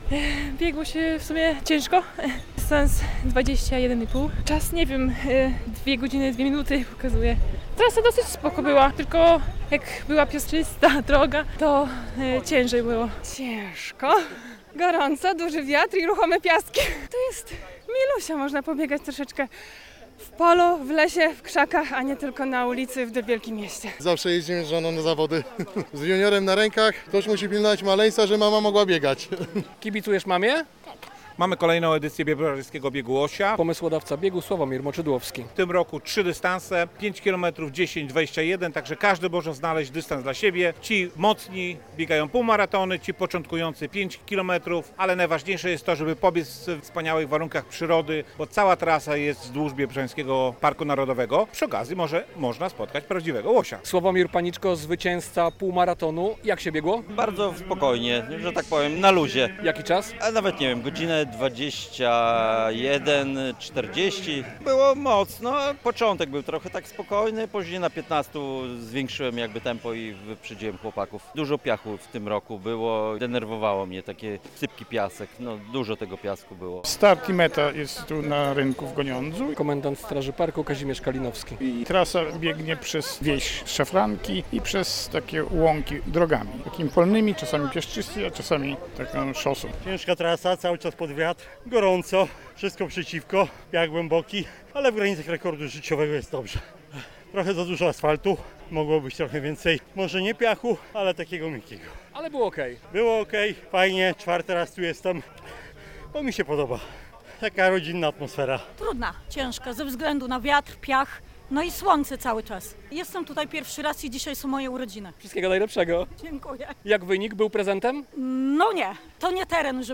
Biebrzański Bieg Łosia - relacja